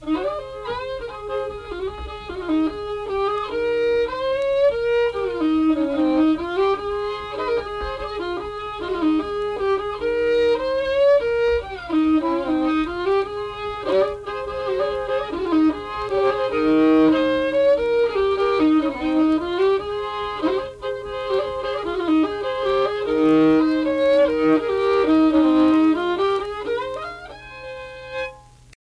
Unaccompanied fiddle music and accompanied (guitar) vocal music
Instrumental Music
Fiddle